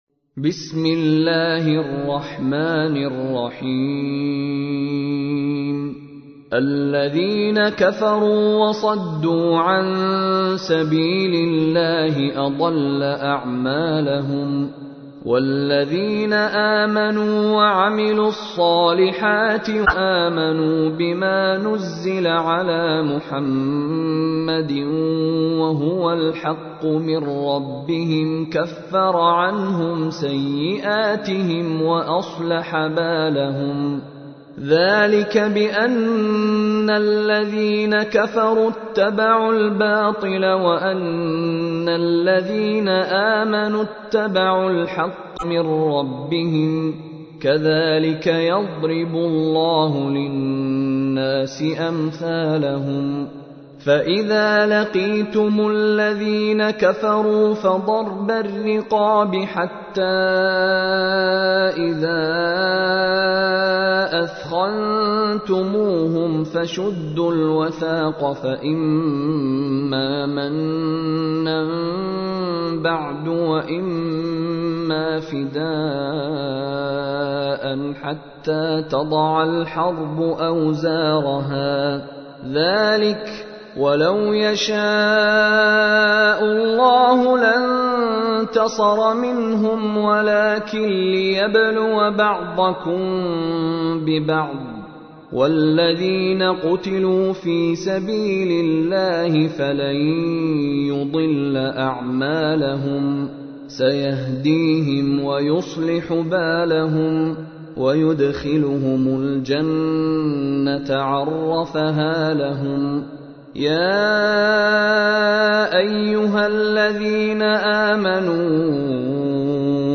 تحميل : 47. سورة محمد / القارئ مشاري راشد العفاسي / القرآن الكريم / موقع يا حسين